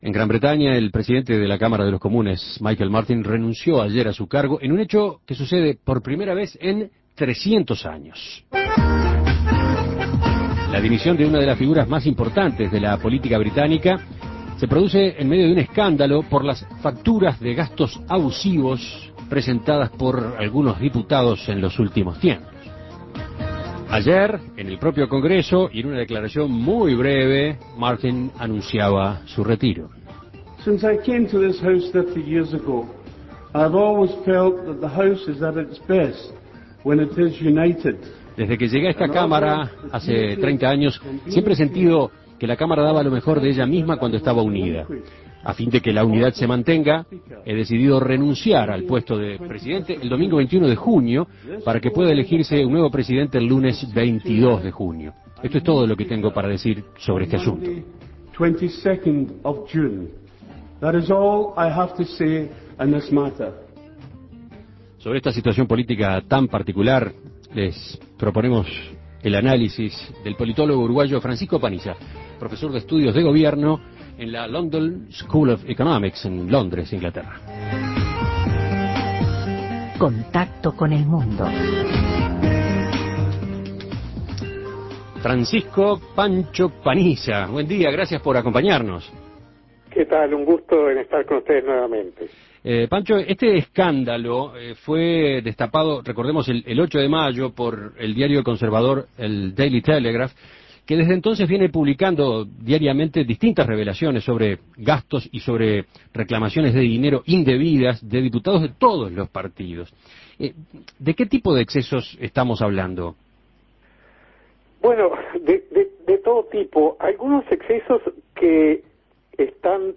Escuche el contacto con el politólogo